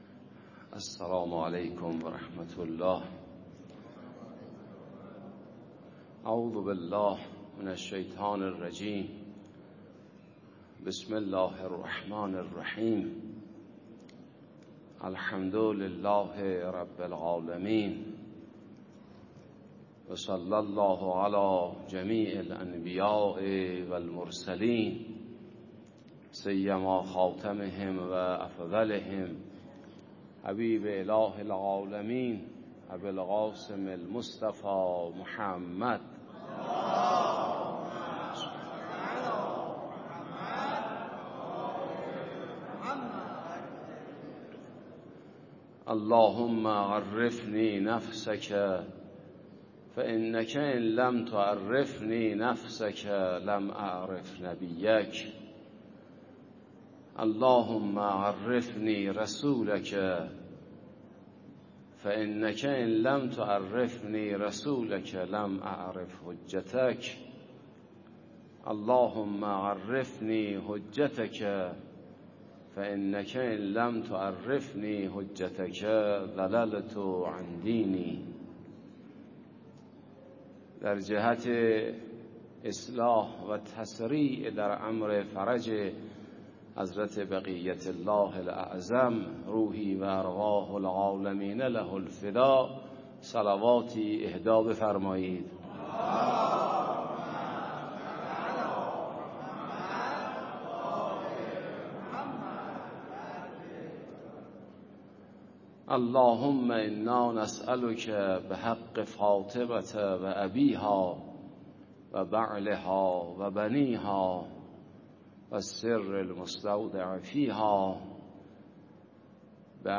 سخنرانی به مناسبت ایام فاطمیه در مسجد امام حسن عسکری تهران شب دوم + صوت